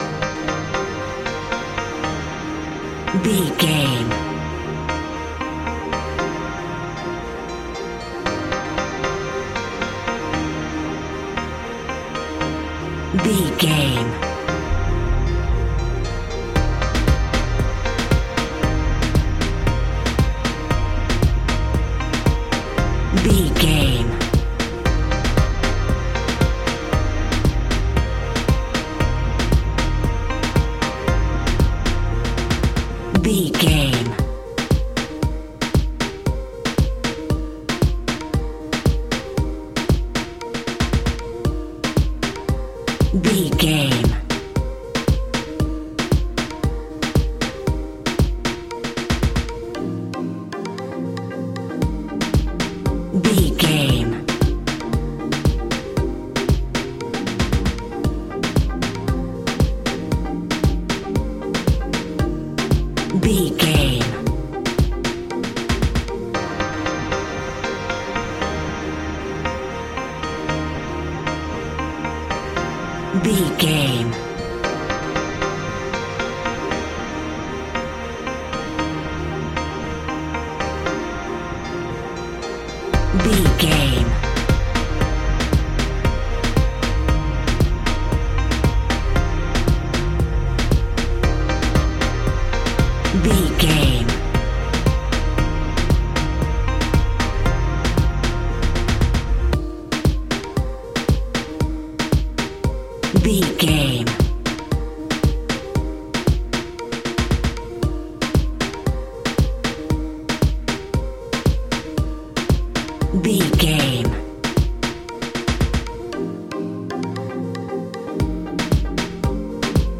Classic reggae music with that skank bounce reggae feeling.
Ionian/Major
D
laid back
chilled
off beat
drums
skank guitar
hammond organ
percussion
horns